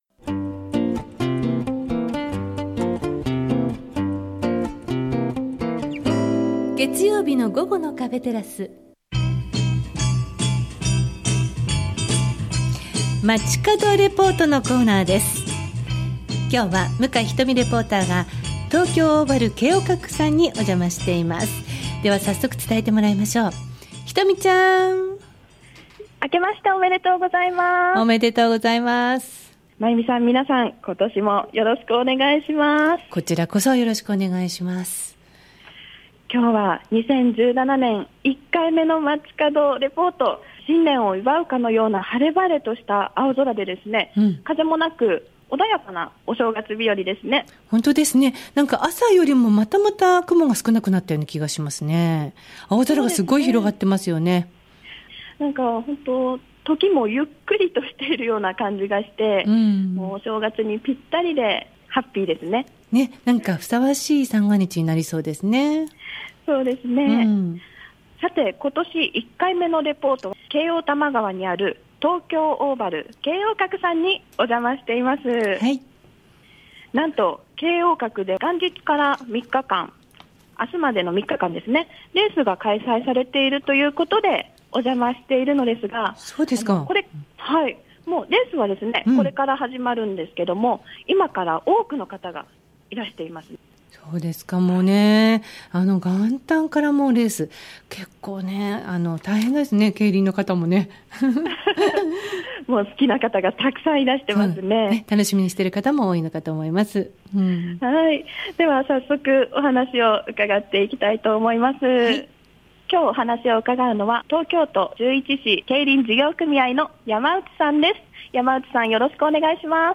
２０１７年も、よろしくお願い致します♪ 新年１回目のレポートは、京王多摩川駅すぐ、 東京オーヴァル京王閣 からお伝えしました！！